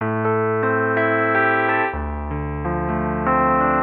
PIANO005_VOCAL_125_A_SC3(R).wav